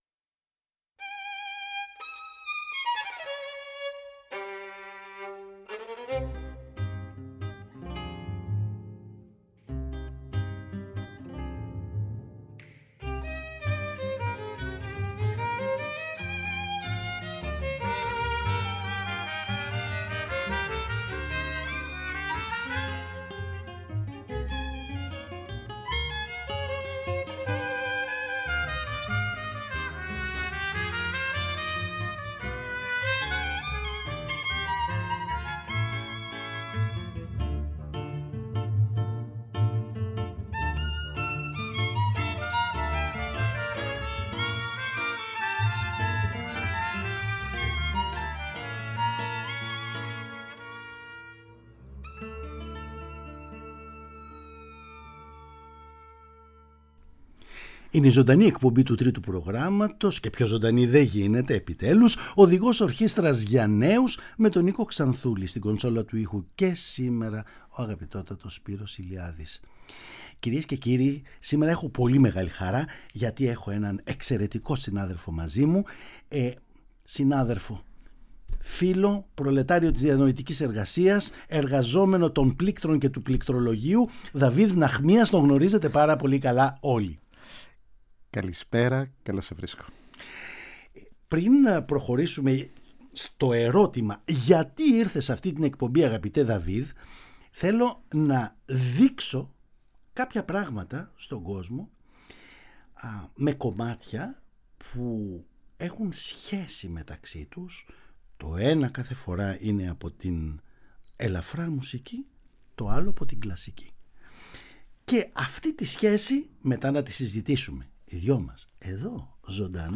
Μια συζήτηση
Ενα θέμα που σε συνδυασμό με ακουστικά παραδείγματα, αποτελούσε εδώ και καιρό πρόκληση για την εκπομπή.
Παραγωγή-Παρουσίαση: Νίκος Ξανθούλης